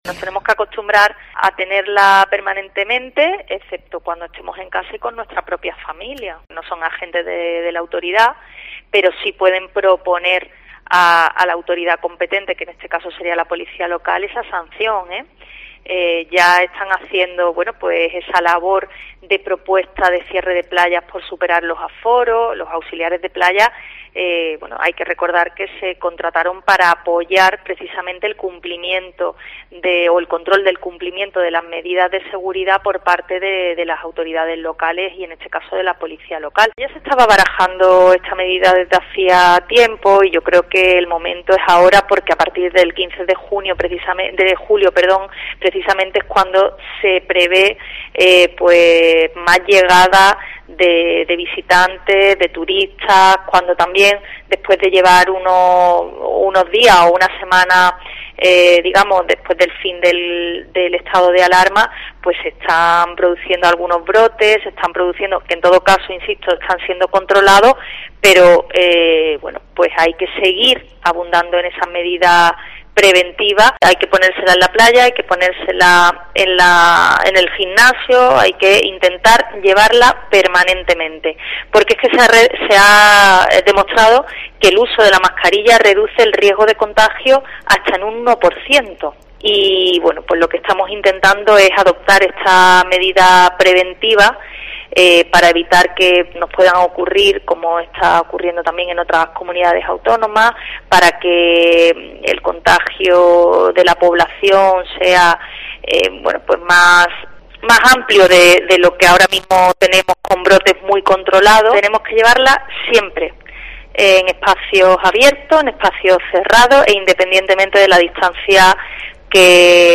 Este mensaje lo envía Patricia Navarro, la delegada provincial de la Junta de Andalucía, que hoy ha aprobado esta medida preventiva ante el coronavirus, que entrará en vigor este miércoles, es decir, esta medianoche, “tenemos que llevarla siempre, en espacios abiertos o cerrados, independientemente de la distancia que haya, hasta ahora siempre era una distancia mínima de metro y medio o más y no era necesario, en este caso ya hacemos de forma extensivo el uso de mascarillas, independientemente de la distancia con otros grupos de personas”, apunta en una entrevista hoy en COPE Más Málaga donde abordó este asunto de calado e importancia general.